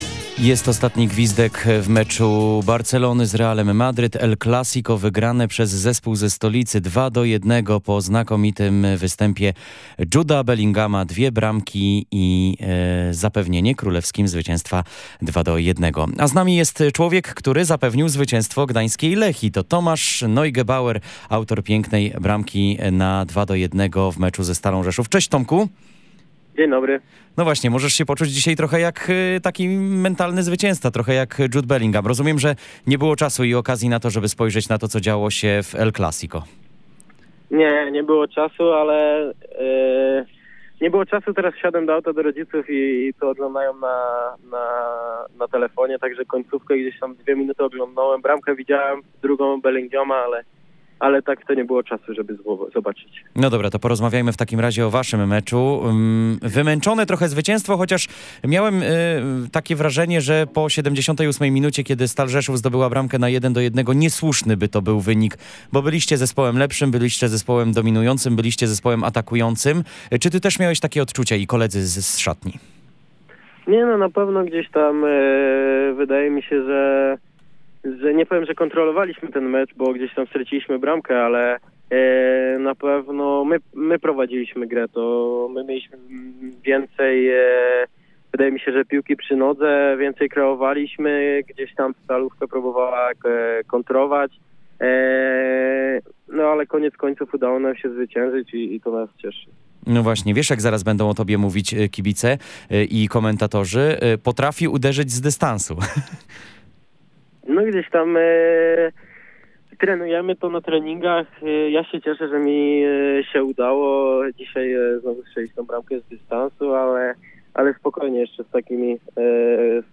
Po spotkaniu połączyliśmy się telefonicznie, pytając o okoliczności zwycięstwa.